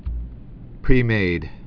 (prēmād)